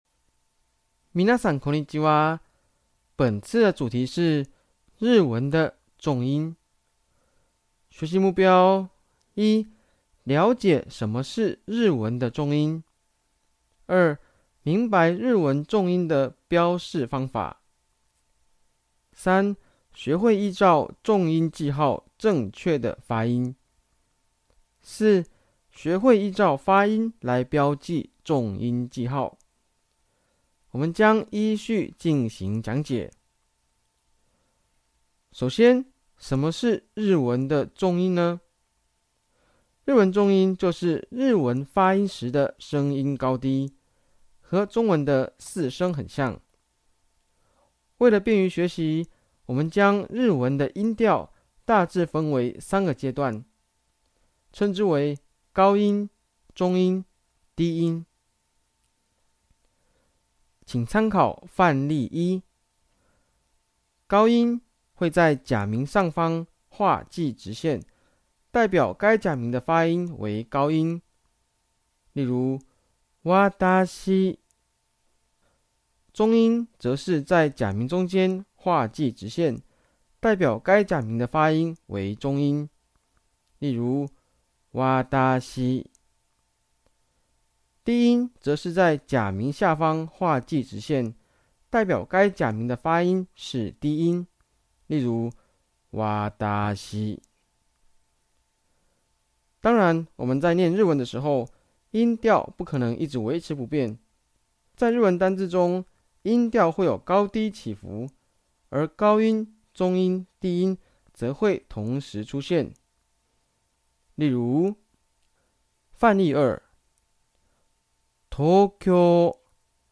聲音解說：